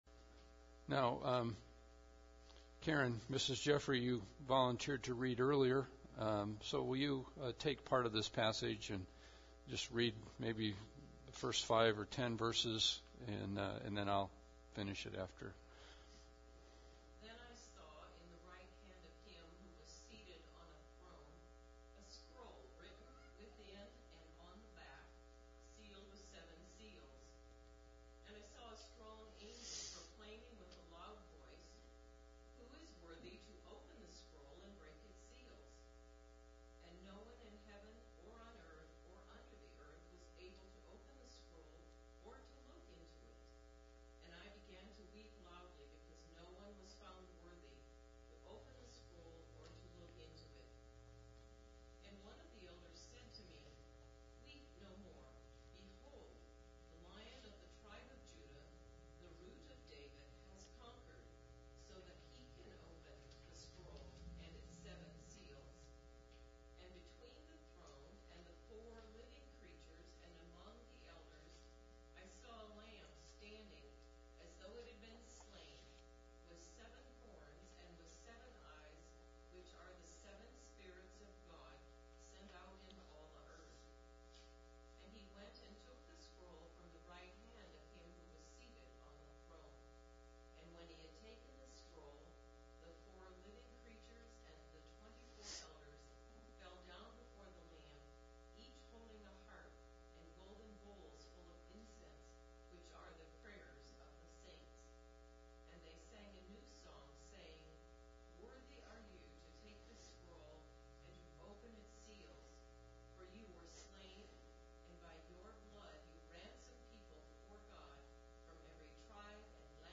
God’s Plan For History – Ascension Day Service
Service Type: Special Service